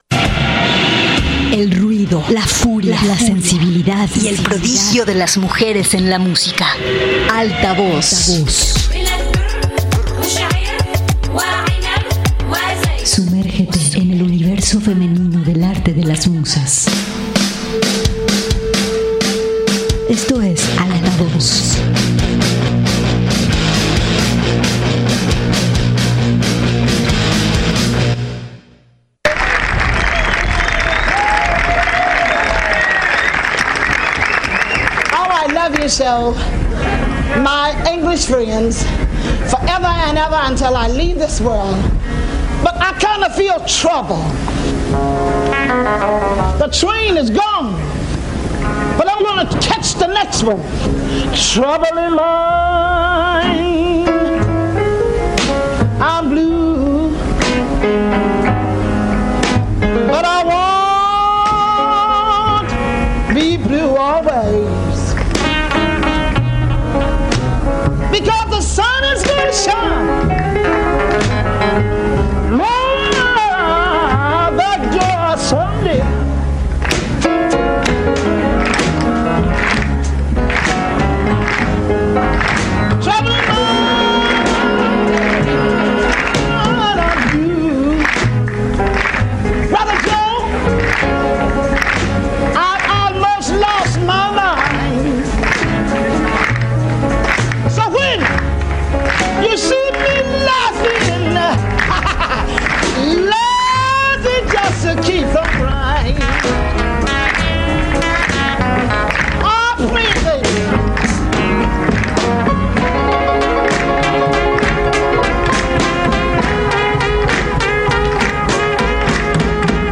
El ruido, la furia, la sensibilidad y el prodigio de las mujeres en la música y la literatura, se hacen presentes todos los miércoles a las 7:00 de la tarde, por el 104.7 de FM, Radio Universidad de Guadalajara en Colotlán.